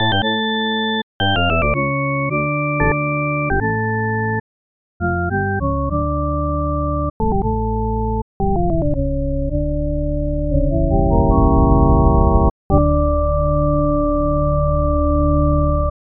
Pipe Organ
OrganPipe.mp3